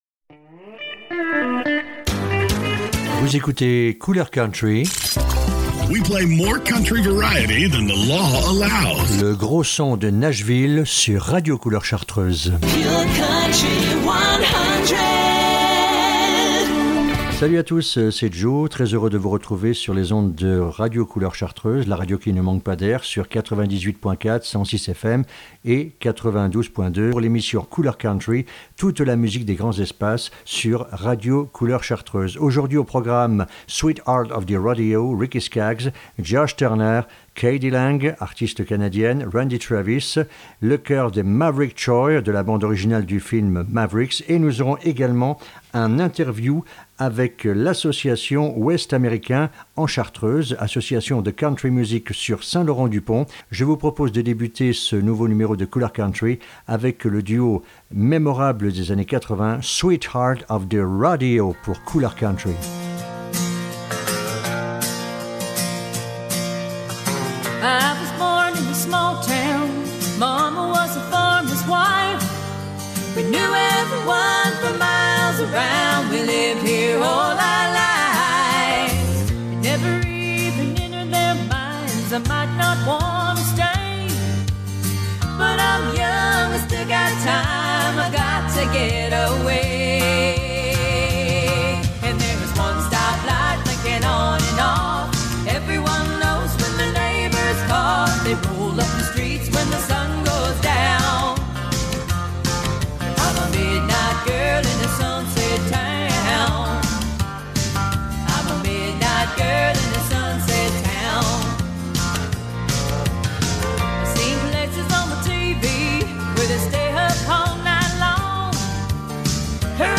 Couleur country avec une belle musique venue des grands Espaces, Ceux du sud des Etats unis :